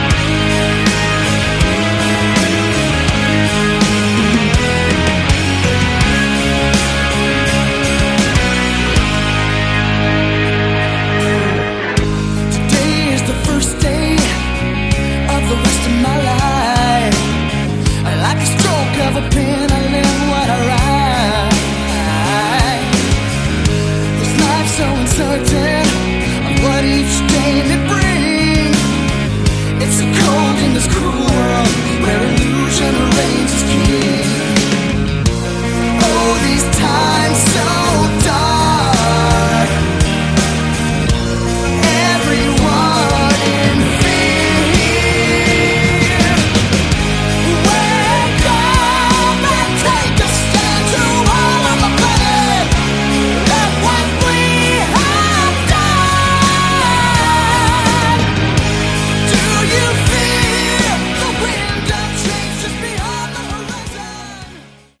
Category: Melodic Power Metal
vocals
guitars
keyboards
bass
drums